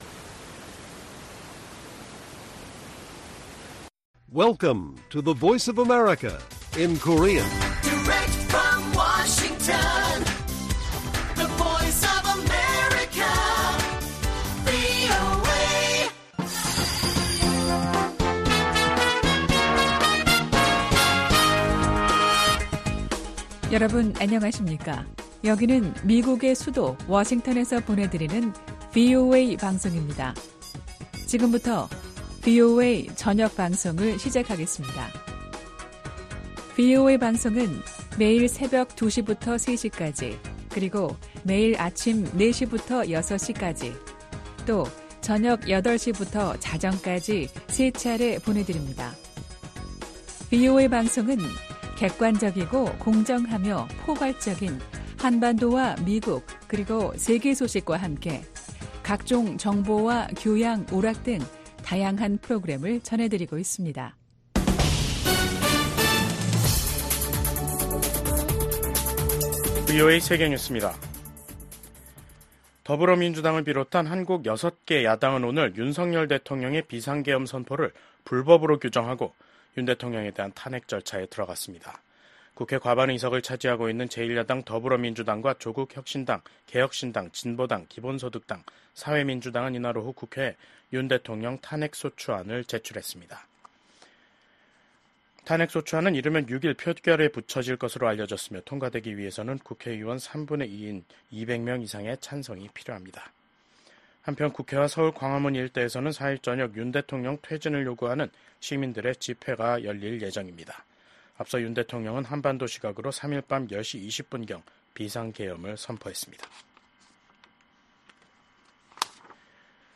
VOA 한국어 간판 뉴스 프로그램 '뉴스 투데이', 2024년 12월 4일 1부 방송입니다. 윤석열 한국 대통령의 비상계엄 선포가 6시간 만에 해제됐지만 정국은 한 치 앞을 내다볼 수 없는 혼돈으로 빠져들고 있습니다. 야당은 윤 대통령의 퇴진을 요구하는 한편 탄핵 절차에 돌입했습니다. 미국 정부는 윤석열 한국 대통령이 법에 따라 비상계엄을 해제한 데 대해 환영의 입장을 밝혔습니다.